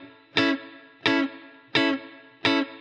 DD_TeleChop_85-Amaj.wav